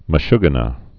(mə-shgə-nə) or me·shug·ge·ner (-nər)